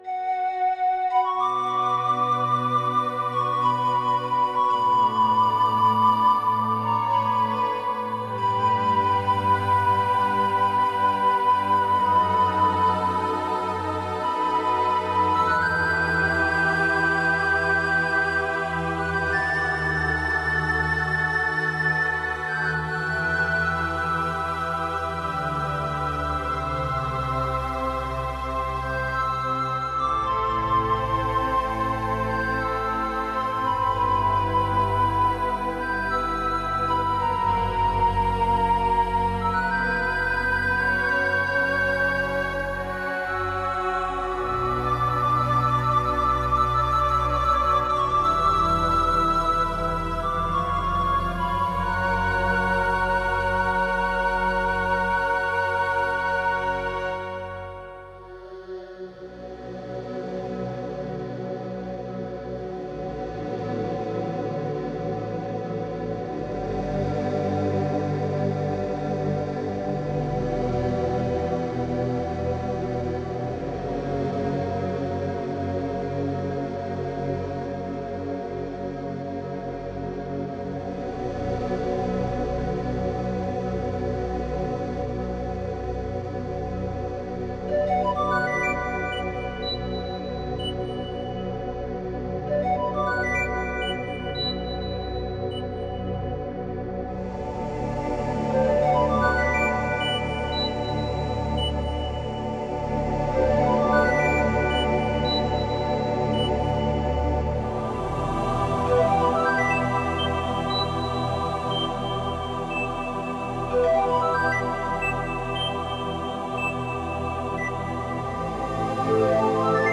varied, nice melodies and sounds from nature
with various instruments